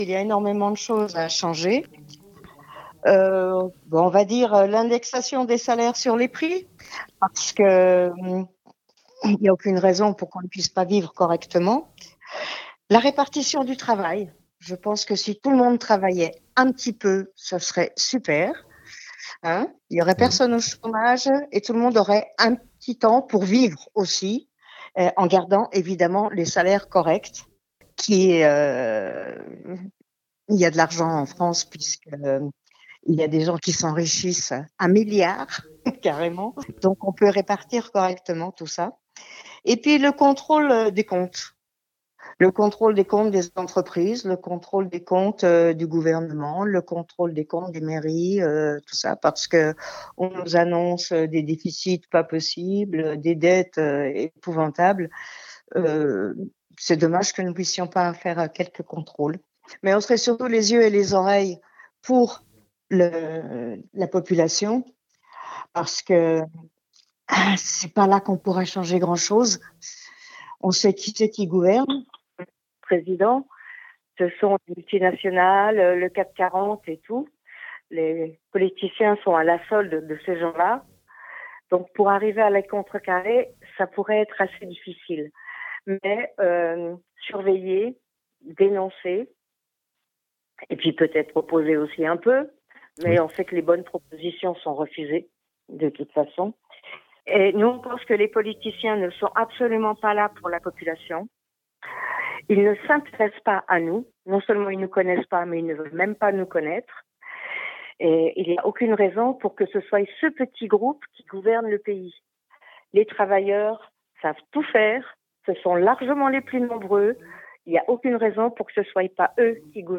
Gros plan sur les candidats du Chablais (interviews)
Voici les interviews des 8 candidats de cette 5ème circonscription de Haute-Savoie (par ordre du tirage officiel de la Préfecture)